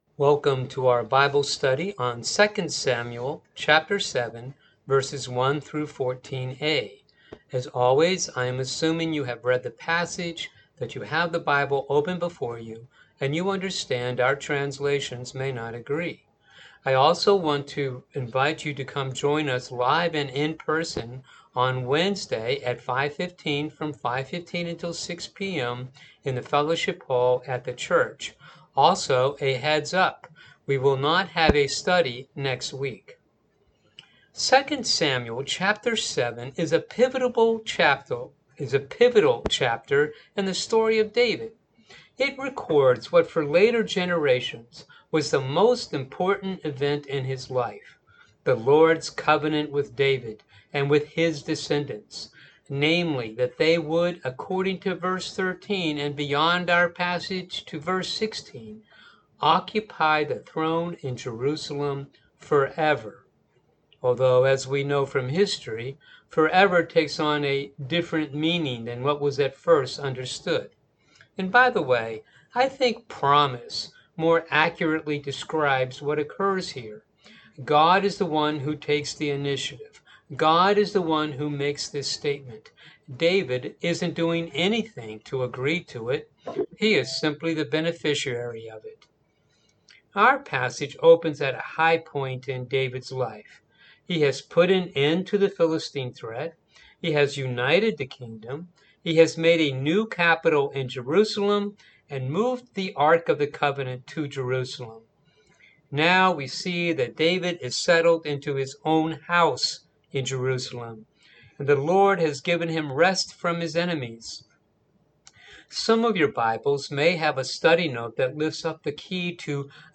Bible Study for the July 18 th Service